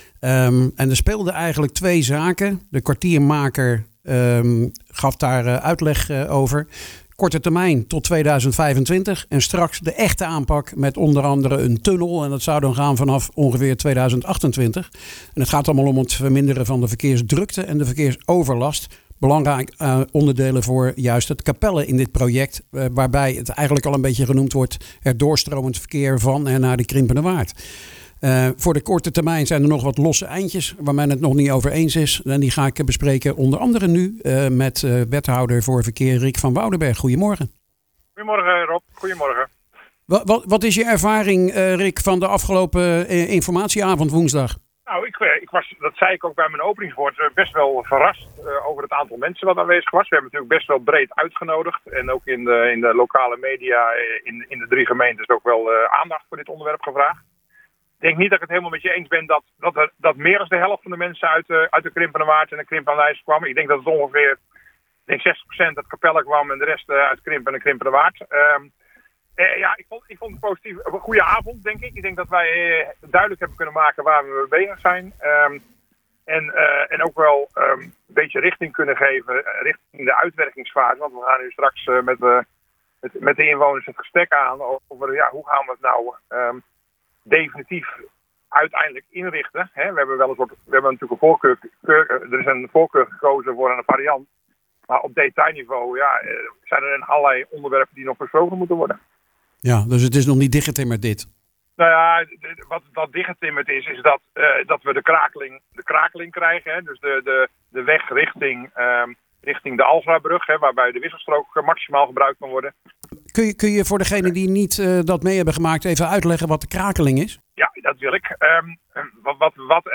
praat in deze podcast met wethouder verkeer Rik van Woudenberg over waar men nu staat en de open eindjes die nog ingevuld worden.